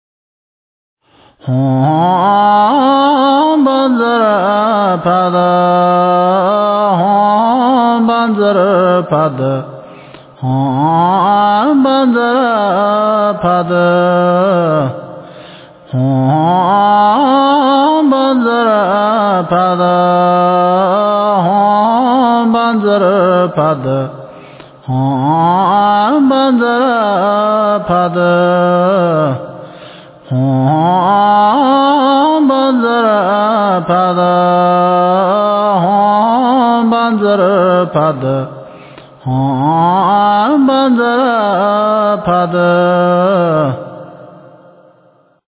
真言
佛音
佛教音乐